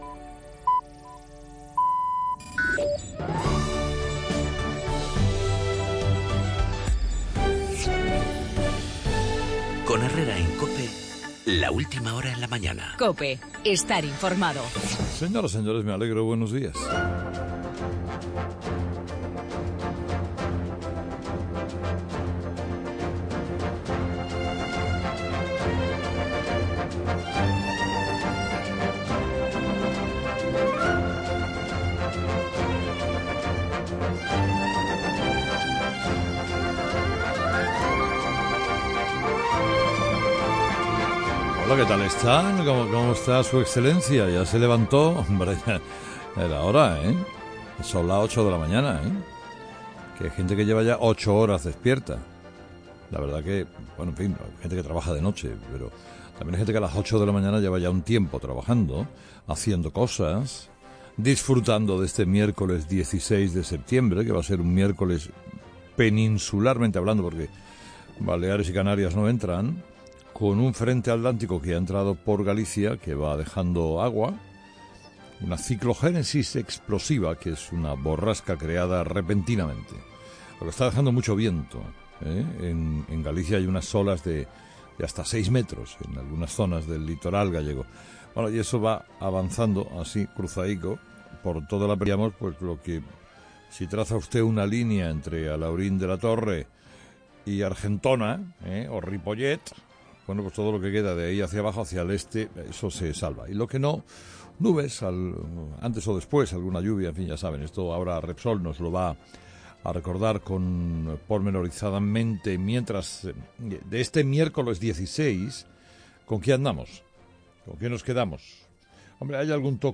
Algo que Carlos Herrera ve razonable, tal y como ha comentado en su editorial de este miércoles, una postura en la que también están Cameron y Merkel.